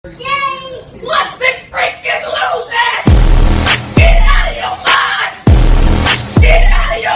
lets mcfreakin lose it ronald mcdonald vine Meme Sound Effect
This sound is perfect for adding humor, surprise, or dramatic timing to your content.